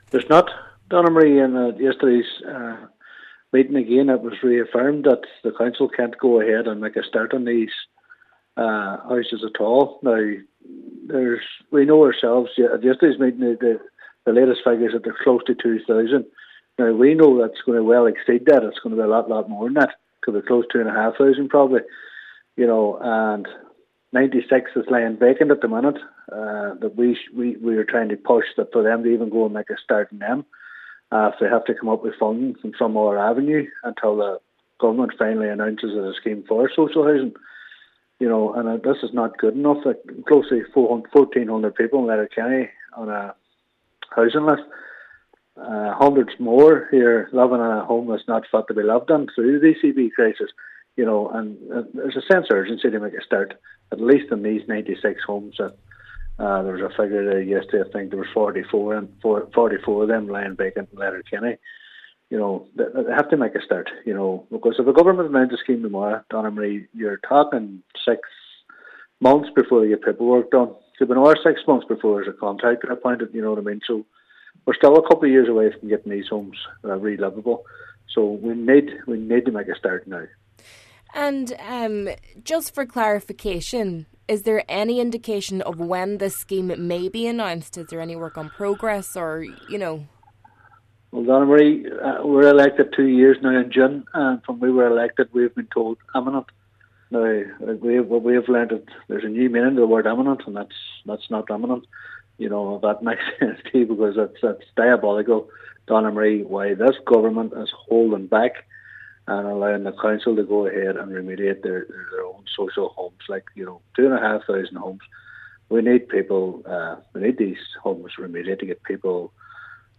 Cllr Devine says this is not good enough: